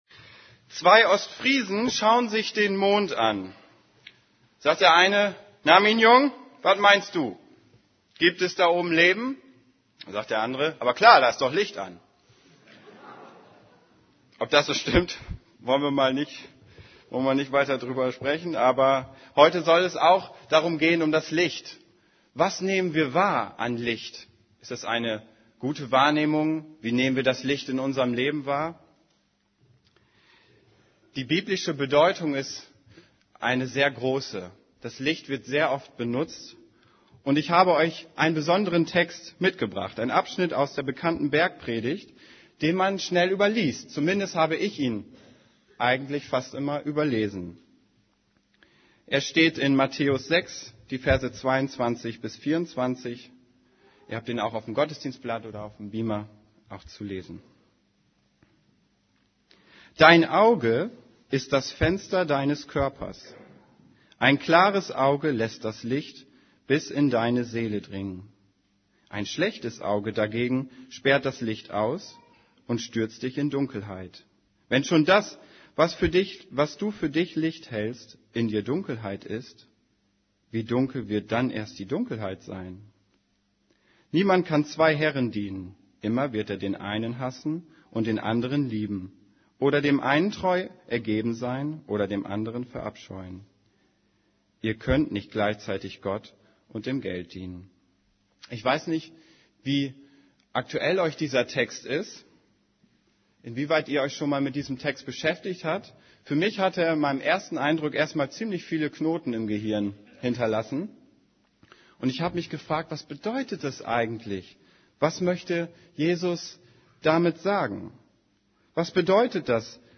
> Übersicht Predigten Wie ich ein Leben im Licht führen kann Predigt vom 06.